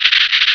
sovereignx/sound/direct_sound_samples/cries/seviper.aif at master